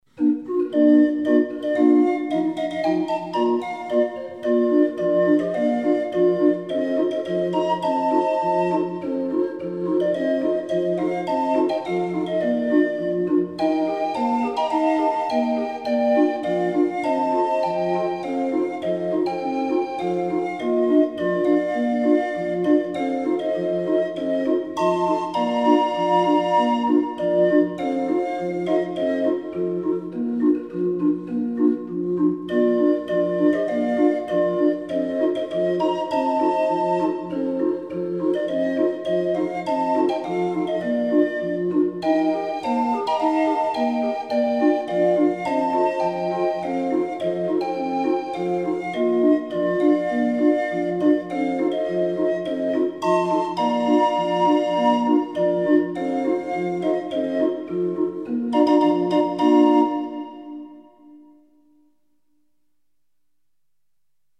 20 street organ